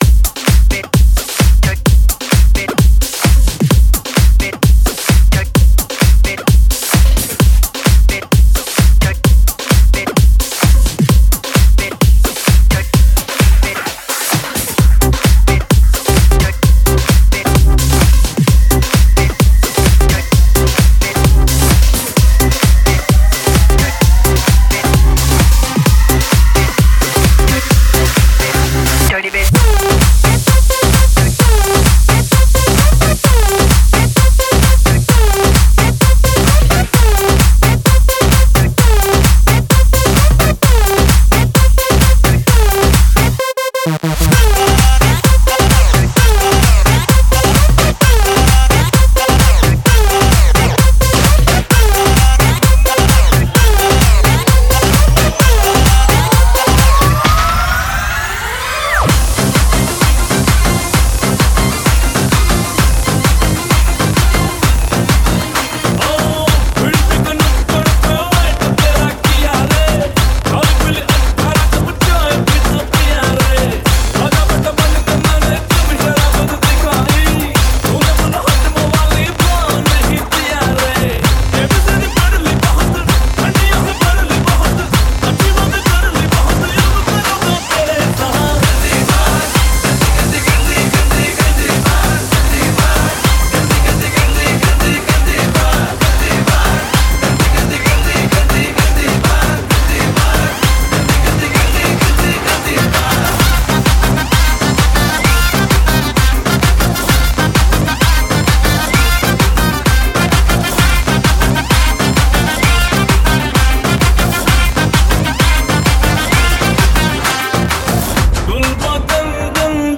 Latest DJ-Mixes